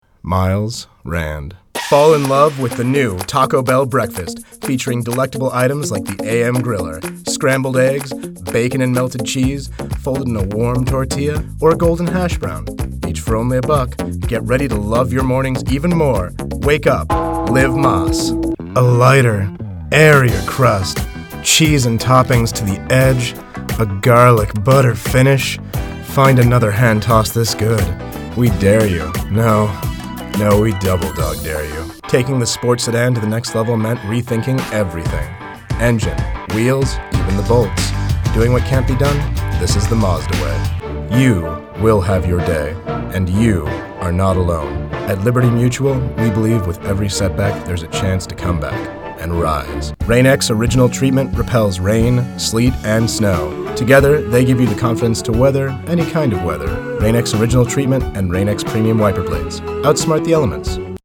Home Recording Studio.
Sprechprobe: Industrie (Muttersprache):